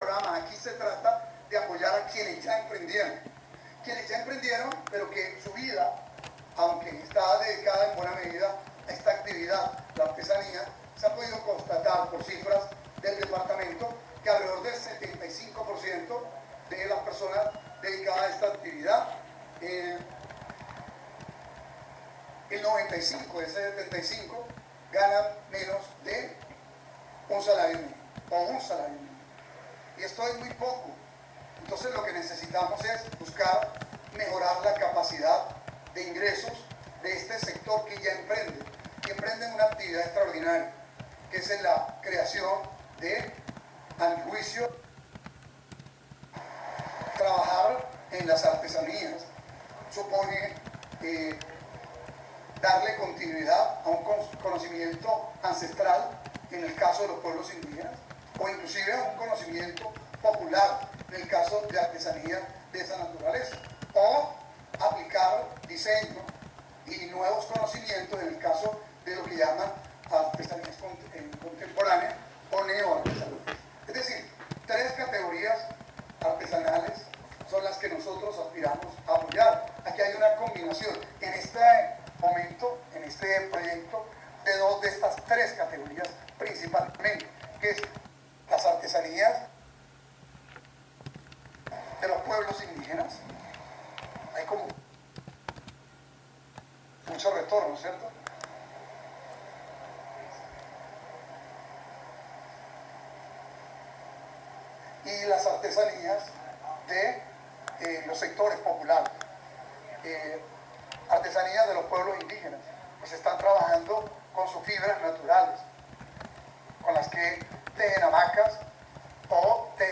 Gobernador-Caicedo-Laboratorio-Artesanias-1-mp3cut.net_.mp3